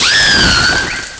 pokeemerald / sound / direct_sound_samples / cries / serperior.aif
-Replaced the Gen. 1 to 3 cries with BW2 rips.